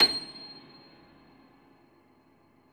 53c-pno26-C6.wav